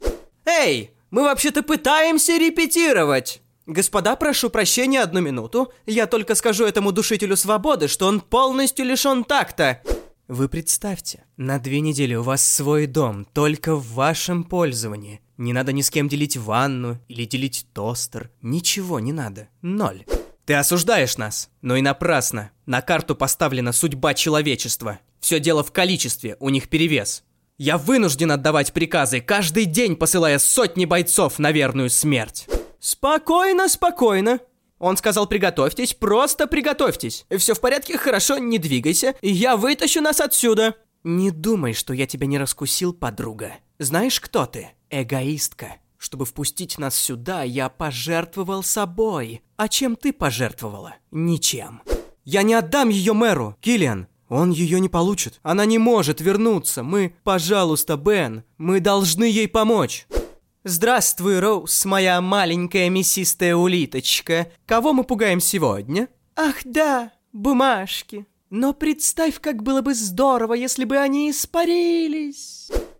17 Октября 2021 17 Октября 2021 17 Октября 2021 17 Октября 2021 Видеовизитка Капитан Алатристе (отрывок) Монти Пайтон - Викторина Фехтование Голос Демо на русском.mp3 Голос Демо на русском.mp3 Скачать файл